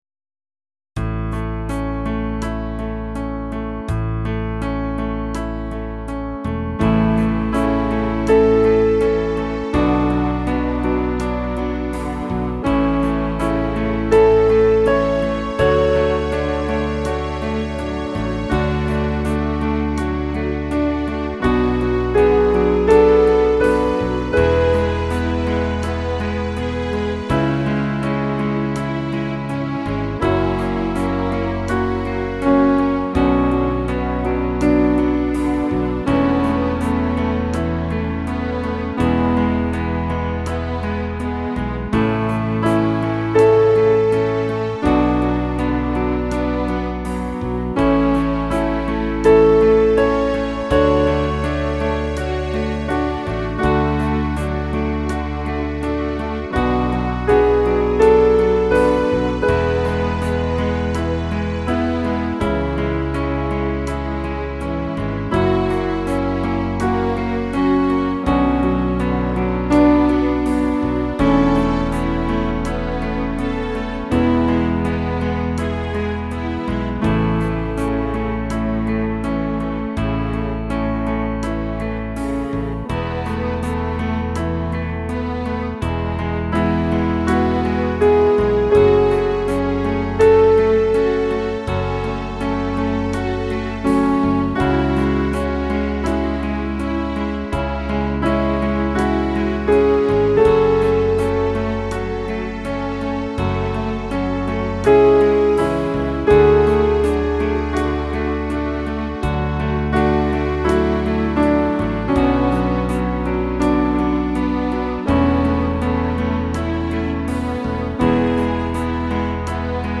Instrumentalaufnahme